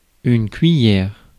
ÄäntäminenFrance (Paris):
• IPA: [yn kɥi.jɛʁ]